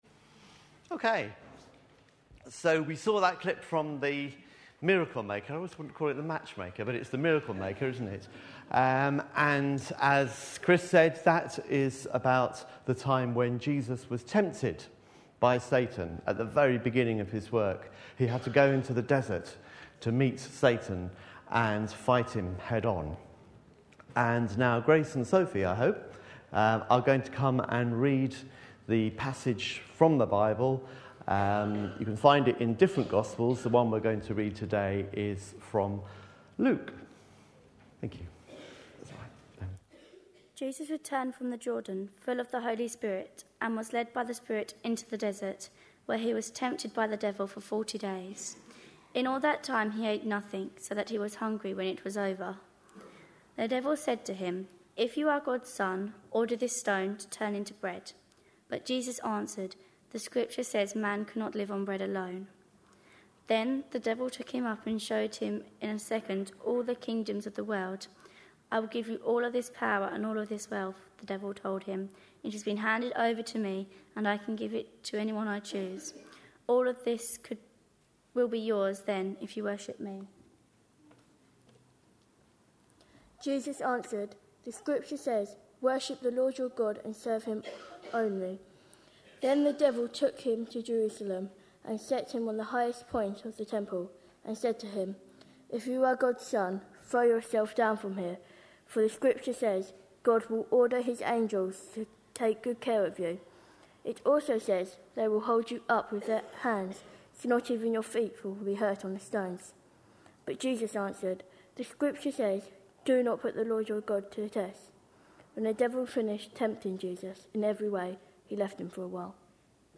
A sermon preached on 17th July, 2011.
Luke 4:1-13 Listen online Details Reading is Luke 4:1-13 ("The Temptation of Jesus"), with references to Ephesians 6:10 onwards ("The Whole Armour of God"), particularly v 17, and Hebrews 4:12. The talk featured a short clip from the Star Wars' film, not included here, and earlier in the all-age service, part of The Miracle Maker' DVD about the Temptation of Jesus was shown.